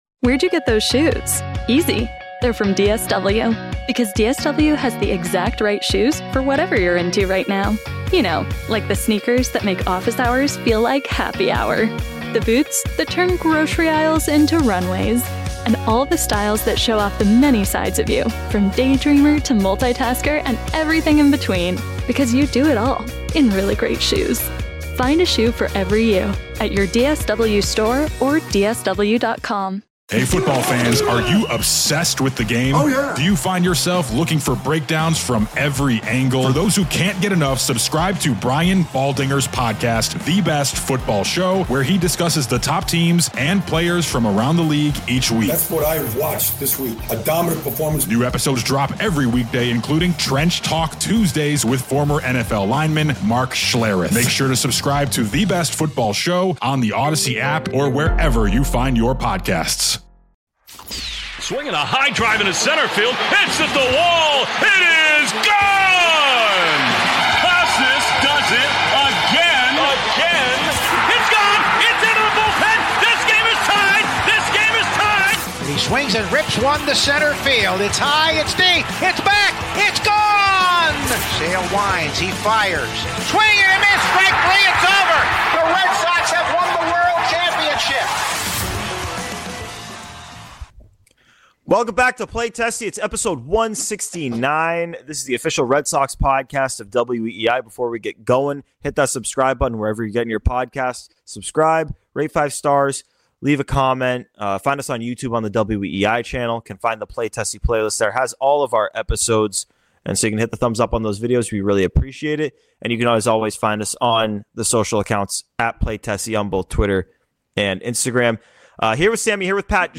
Red Sox-related audio from WEEI shows and podcasts, including postgame interviews.